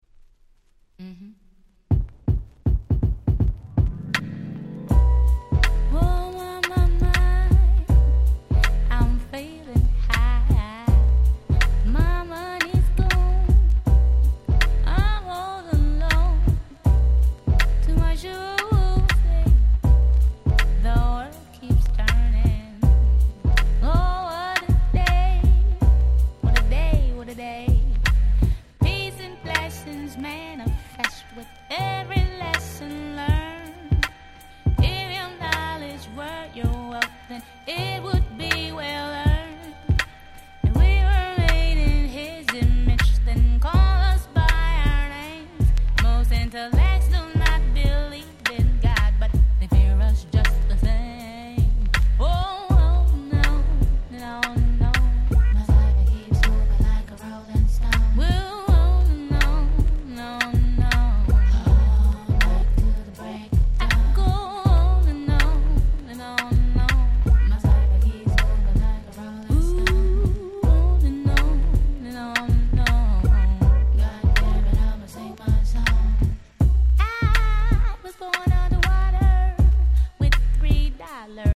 96' Super Hit R&B/Neo Soul !!